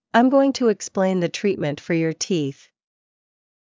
ｱｲﾑ ｺﾞｰｲﾝｸﾞ ﾄｩｰ ｴｸｽﾌﾟﾚｲﾝ ｻﾞ ﾄﾘｰﾄﾒﾝﾄ ﾌｫｰ ﾕｱ ﾃｨｰｽ